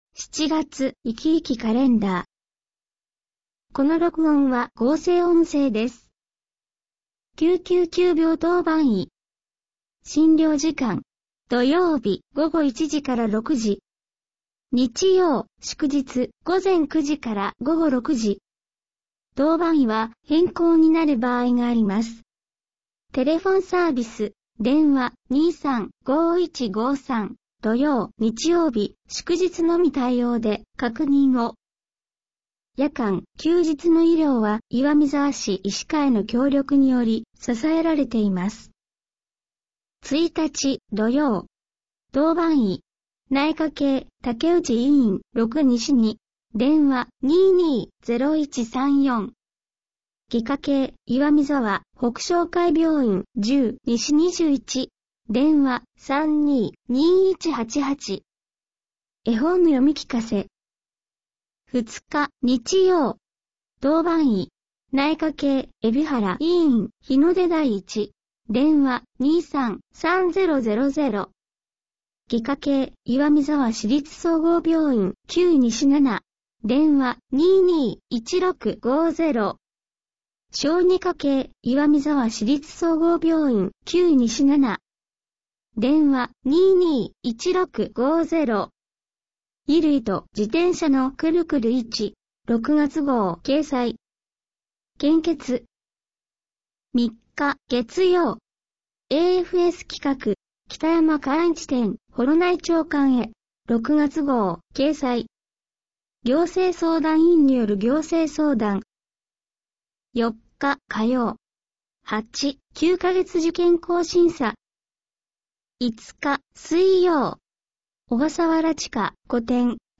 声の広報MP3版は、岩見沢さつきの会の協力で作成しています。